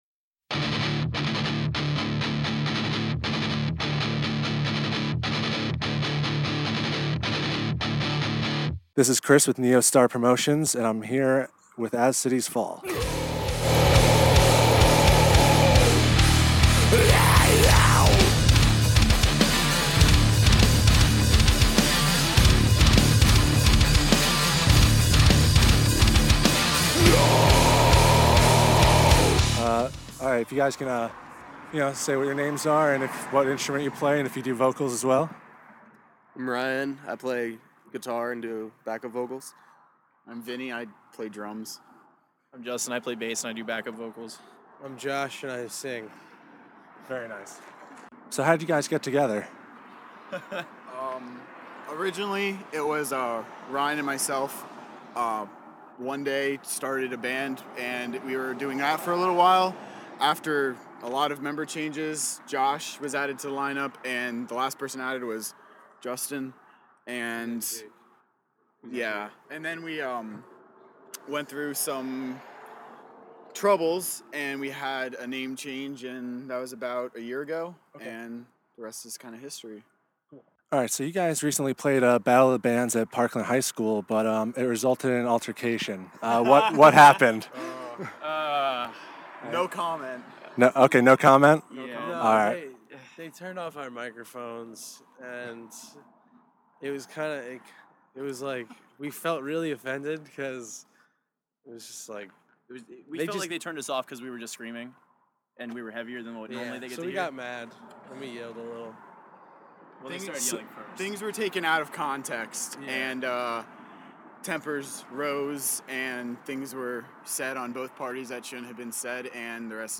Exclusive: As Cities Fall Interview
14-interview-as-cities-fall1.mp3